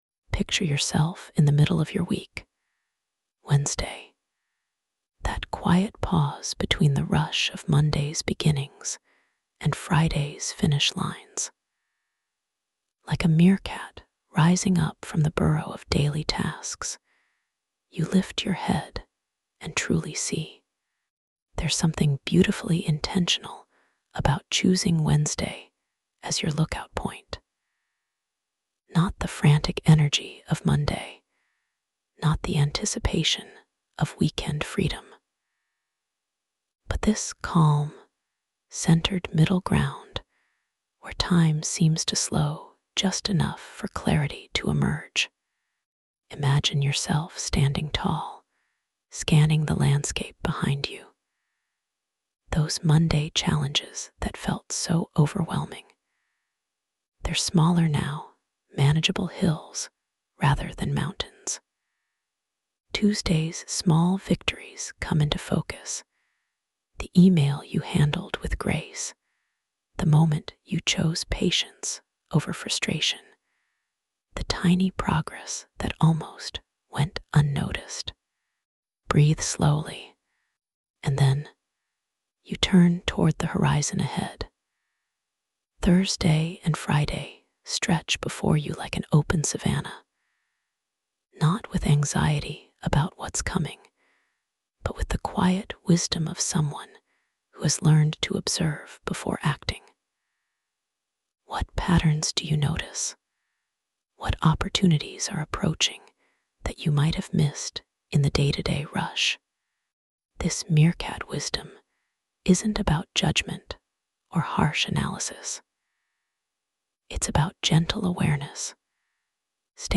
i'm experimenting with ASMR, Meerkats and Wednesdays, this might have to have some brainrot to go with it, then automated.